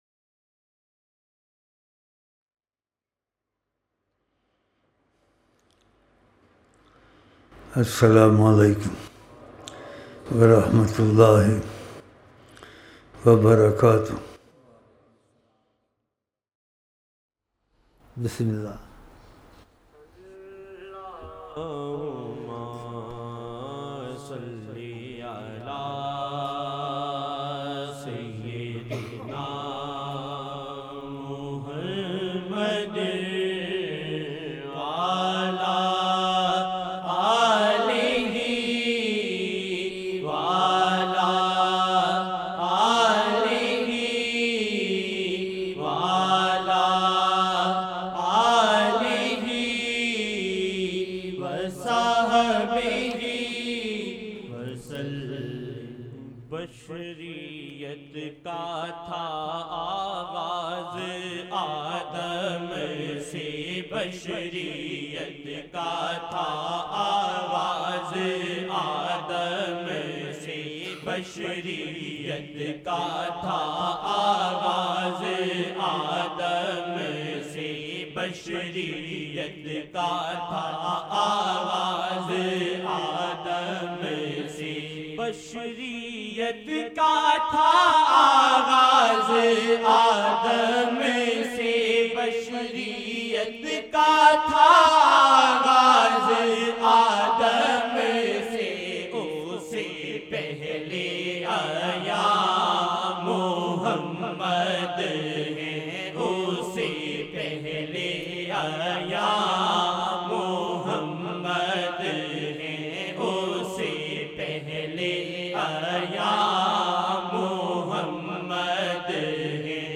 Naat Shareef